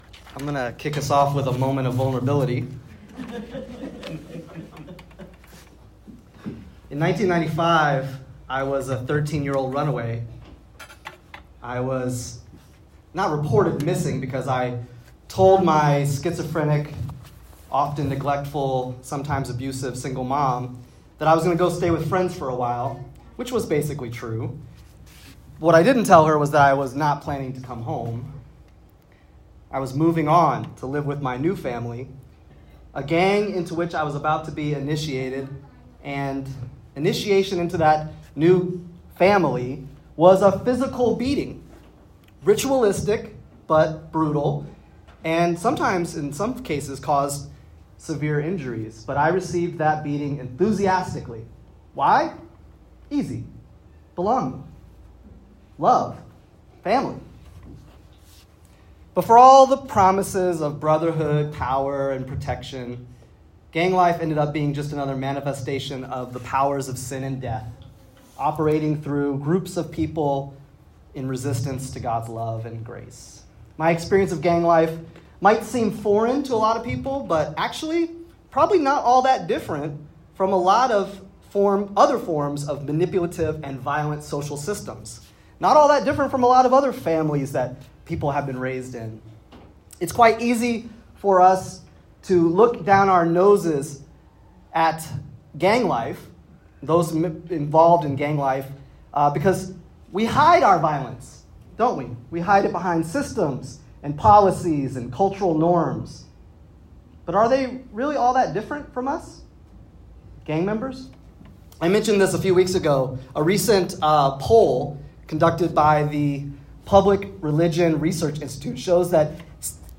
In this powerful and deeply personal sermon, we explore the true meaning of discipleship through the lens of one man’s journey from gang life to following Jesus. Using Mark’s Gospel and insights from Dietrich Bonhoeffer’s The Cost of Discipleship, this message challenges us to leave behind false securities, embrace intimacy with Christ, and take up our cross in a world bent on power and division. As we navigate a time of increasing polarization—even within the Church—this sermon calls us to resist the forces of Sin and Death by living out the radical, self-giving love of Jesus.